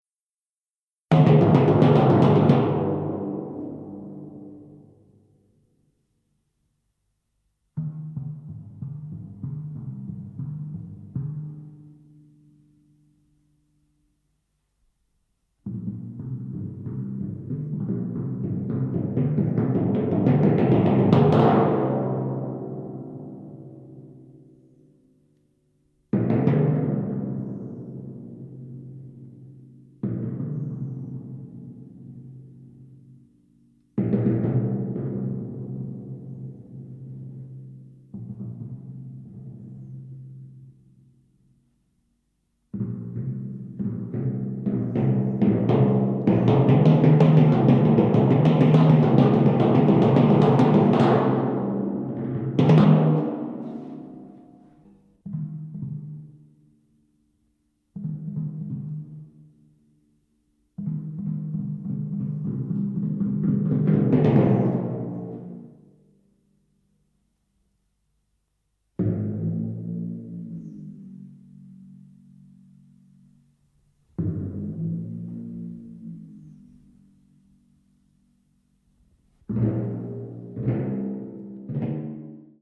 연속적인 글리산도를 연주하는 팀파니 연주자.
글리산도 효과를 사용한 팀파니 독주가 특징인